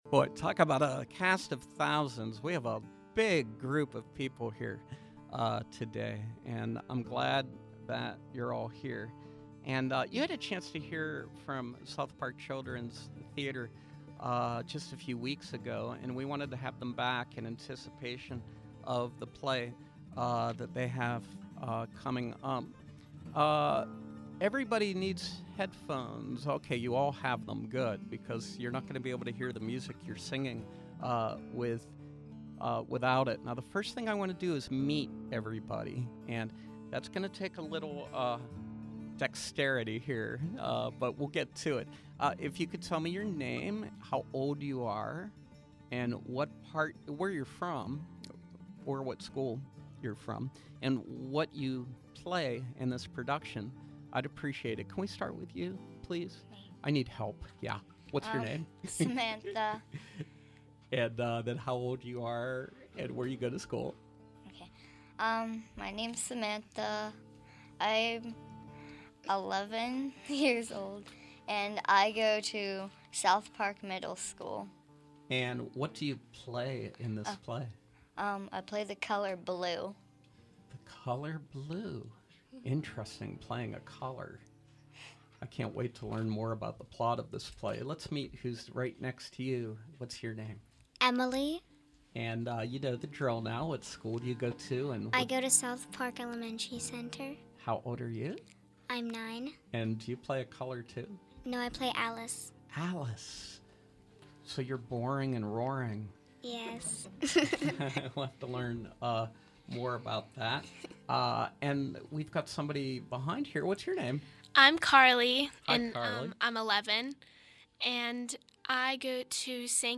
Joining us is the cast of South Park Children’s Theatre, performing selections from their latest production, “Roaring, Boring Alice,” a musical